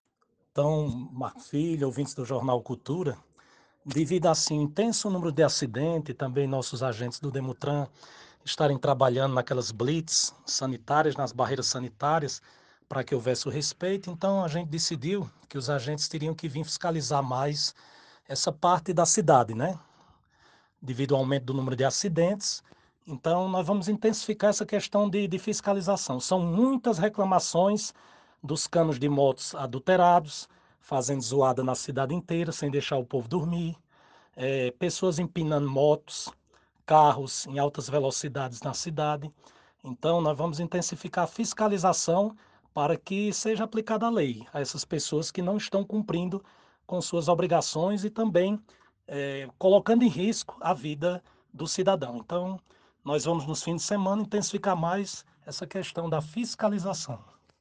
A informação foi confirmada pelo secretário de Infraestrutura do município Elonmarcos Correia, à FM Cultura na tarde desta sexta-feira, 17.